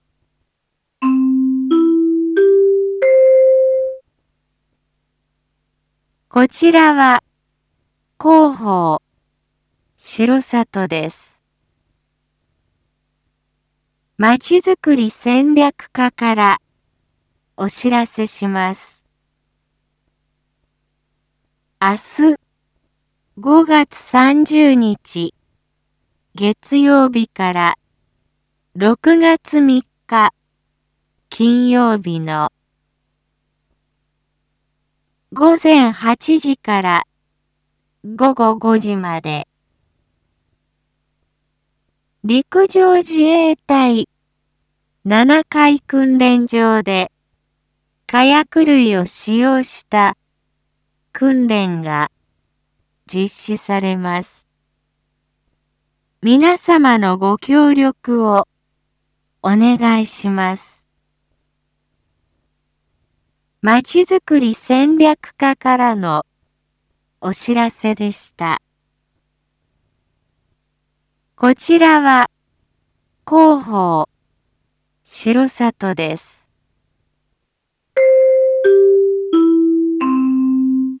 Back Home 一般放送情報 音声放送 再生 一般放送情報 登録日時：2022-05-29 19:01:22 タイトル：R4.5.29 19時放送 インフォメーション：こちらは広報しろさとです。